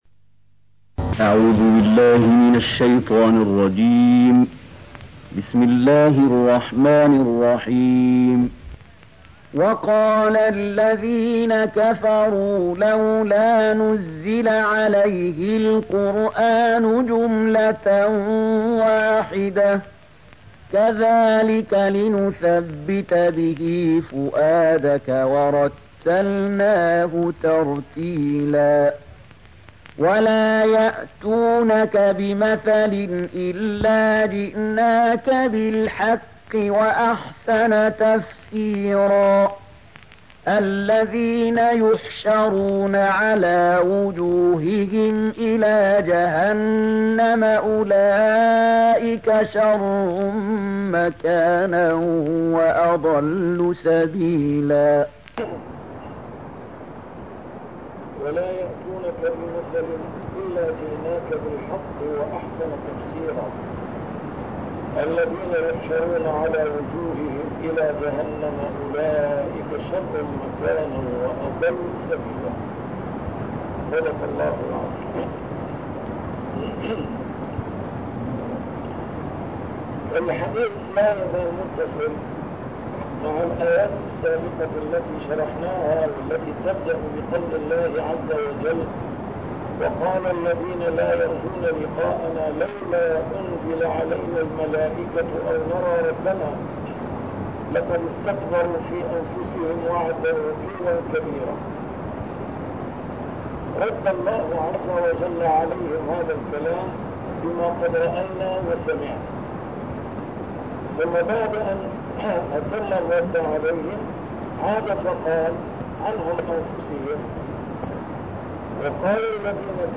A MARTYR SCHOLAR: IMAM MUHAMMAD SAEED RAMADAN AL-BOUTI - الدروس العلمية - تفسير القرآن الكريم - تسجيل قديم - الدرس 210: الفرقان 32-34